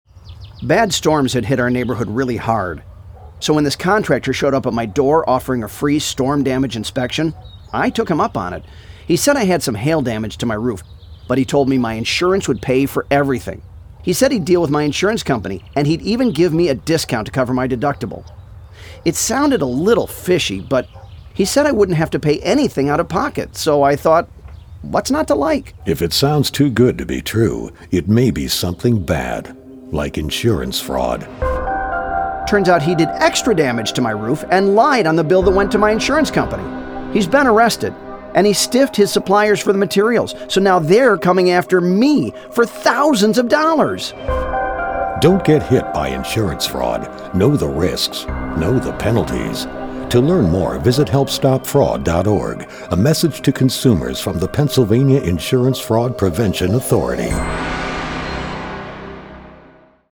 Voice Talent’s Everyman Portrayal Features Victim of Home Contractor Scam